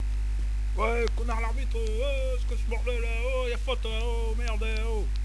bande son du match
son match.wav